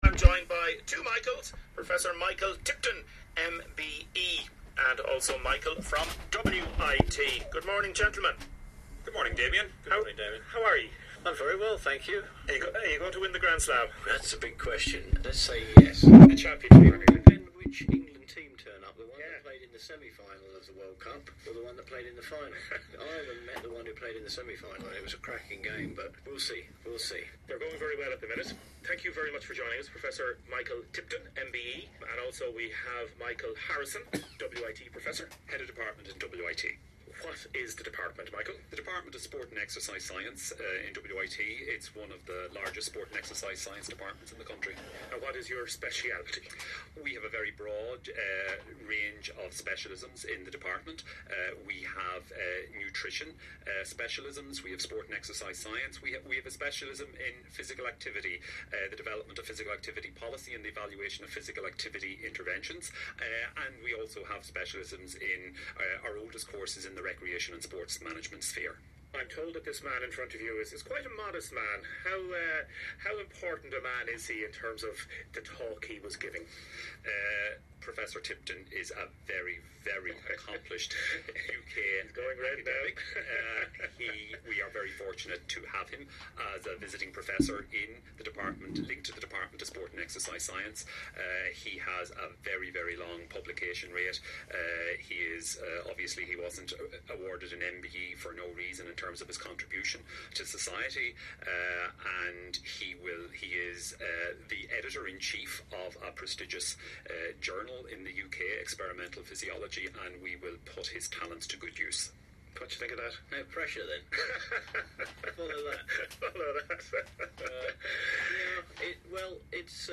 WLRfm Interview